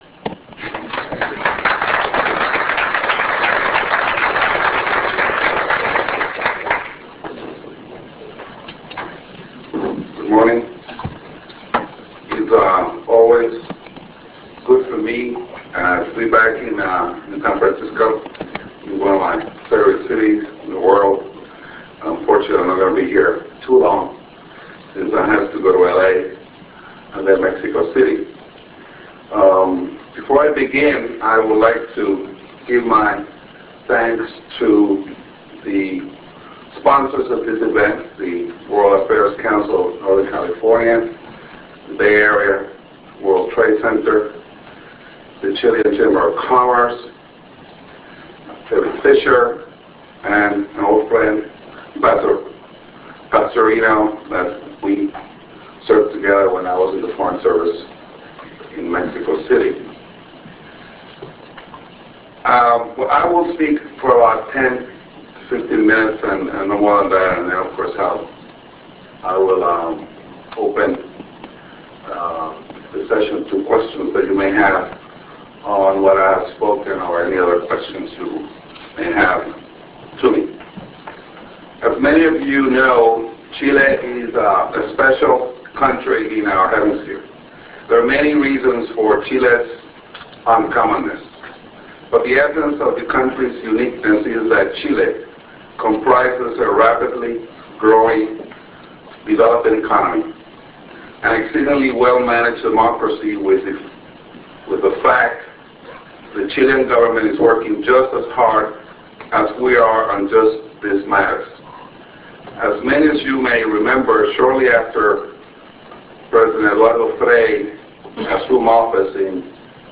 Entire Presentation